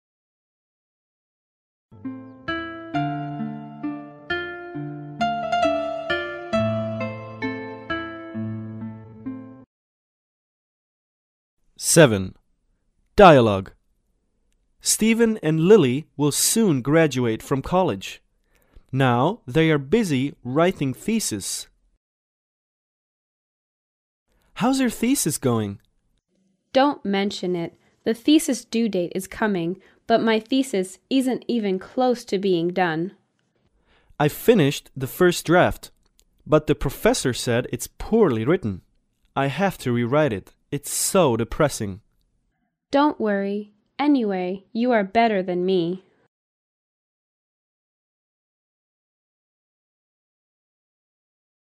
对话